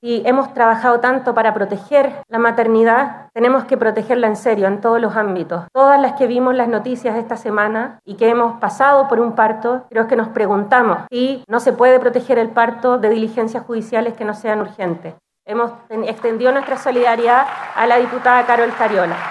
Así lo señalaron ambas autoridades de Gobierno en medio de una actividad realizada en la comuna de Quinta Normal.